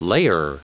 Prononciation du mot layer en anglais (fichier audio)
Prononciation du mot : layer